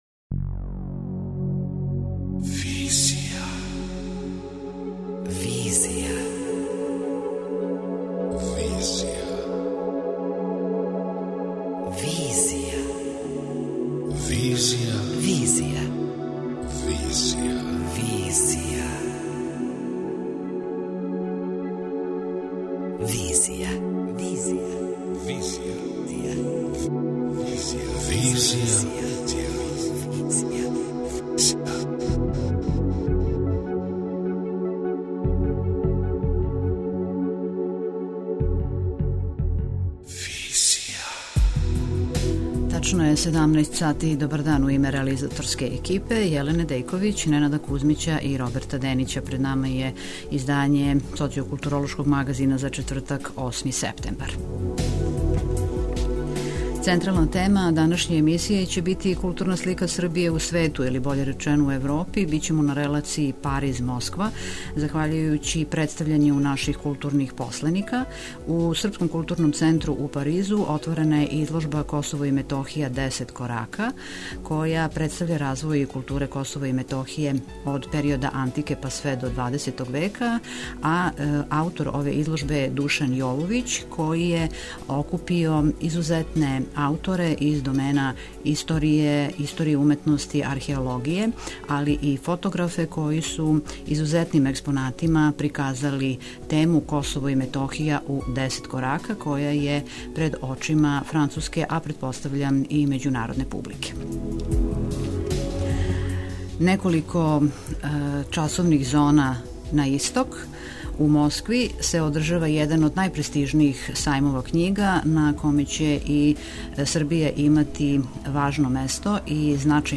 преузми : 27.30 MB Визија Autor: Београд 202 Социо-културолошки магазин, који прати савремене друштвене феномене.